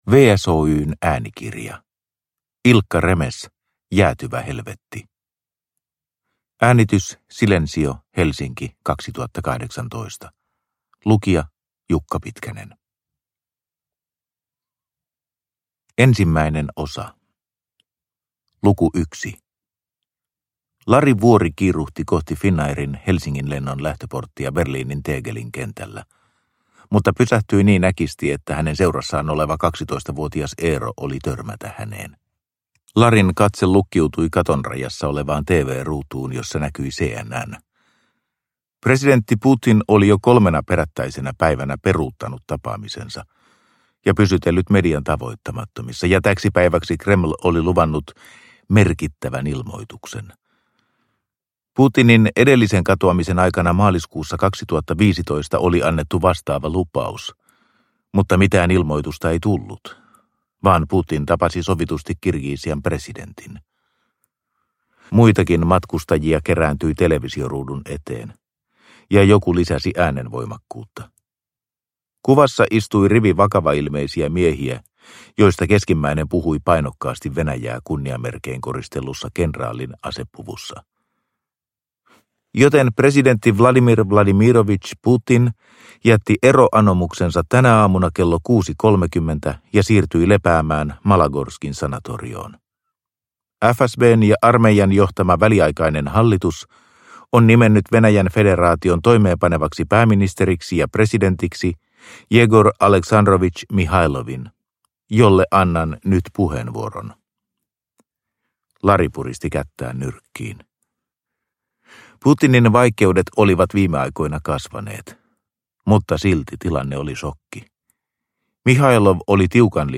Jäätyvä helvetti – Ljudbok – Laddas ner